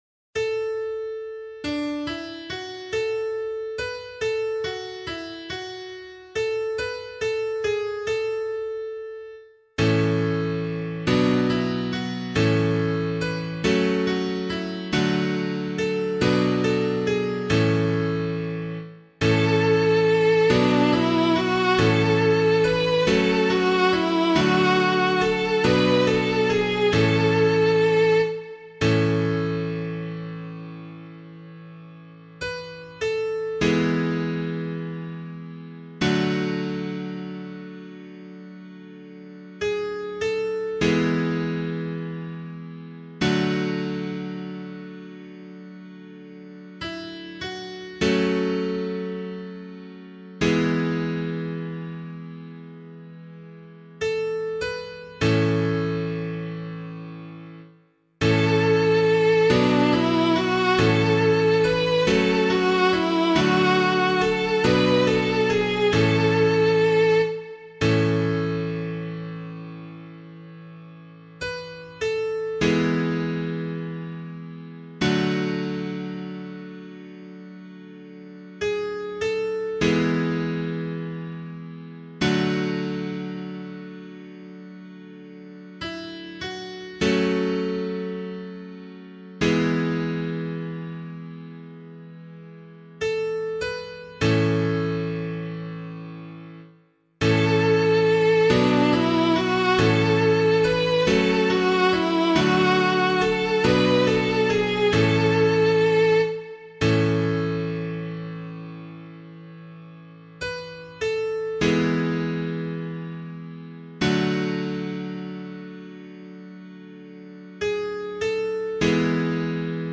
022 Easter Vigil Psalm 1A [LiturgyShare 8 - Oz] - piano.mp3